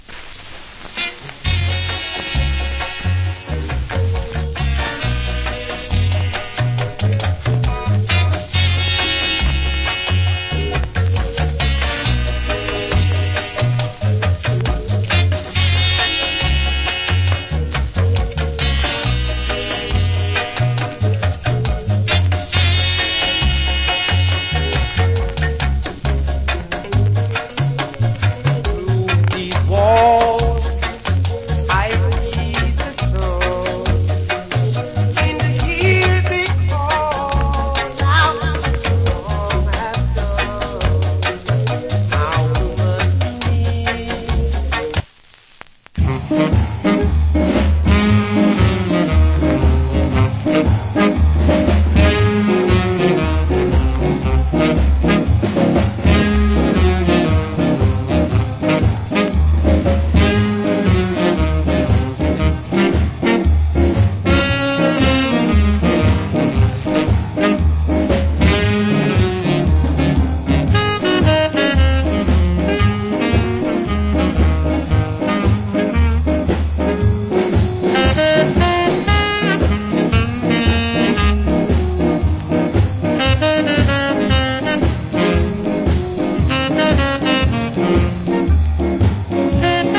Wicked Reggae Vocal.